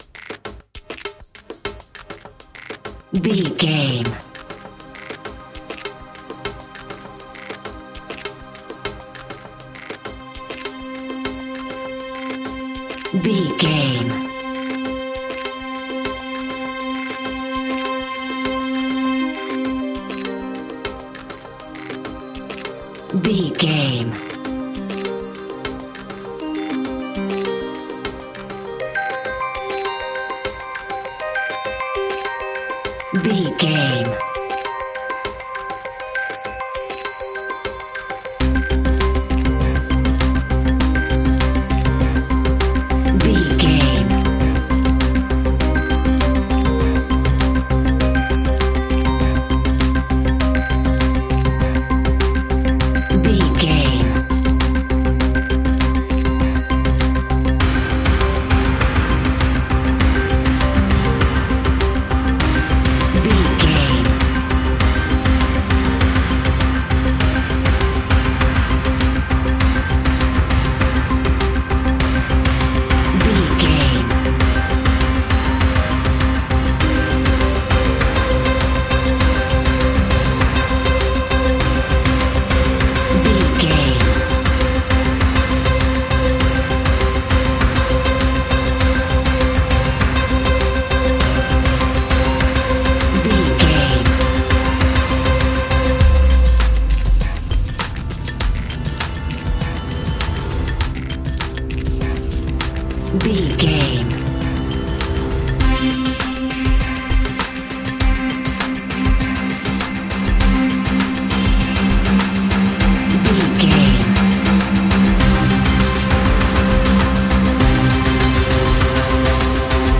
Ionian/Major
electronic
techno
trance
synths
synthwave
instrumentals